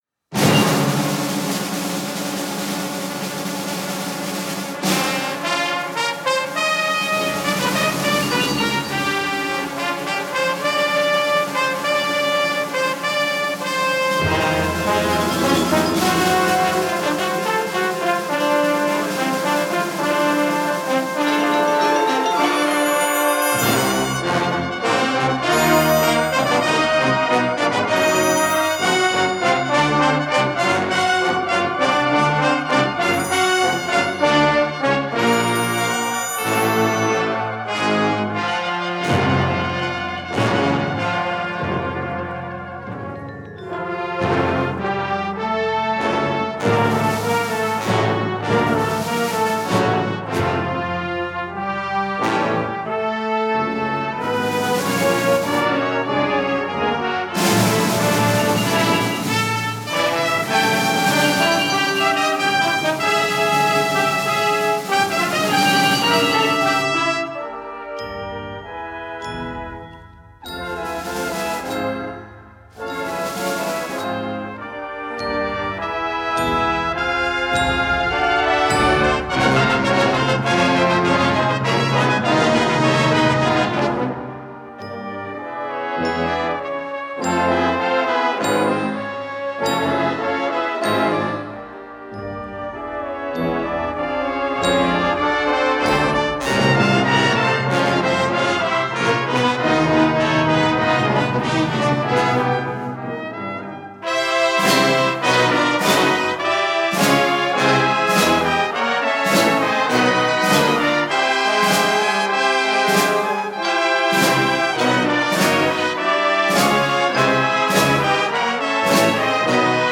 Brass Band Series